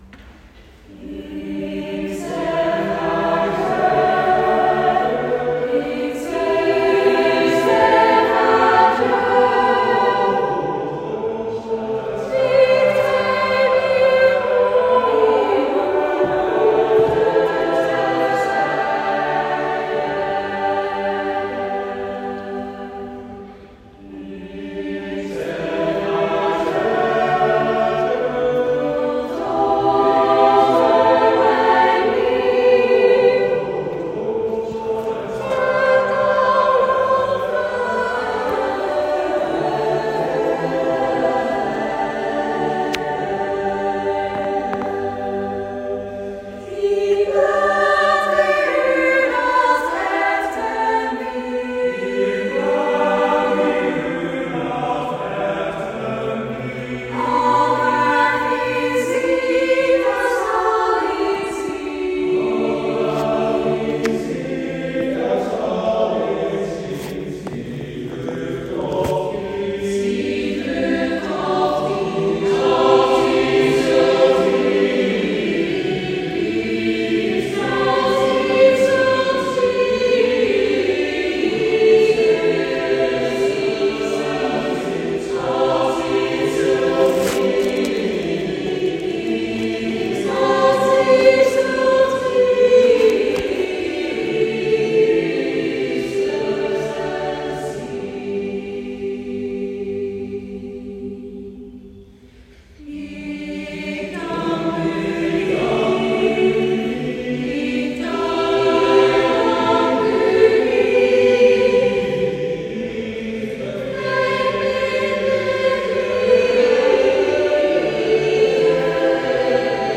En muzikale pelgrimstocht, een live uitgevoerd concept-album.
die de jonge zangers en zangeressen naar grote harmonieuze hoogten tilt, daarbij geruggesteund door de weldadige akoestiek van de kleurrijke Sint-Willibrorduskerk
Een bootleg-opname van Ic Seg Adiu, Vierakker, 14 juni 2025 (hier en daar wat bijgeluid):